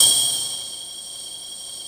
45 METAL  -L.wav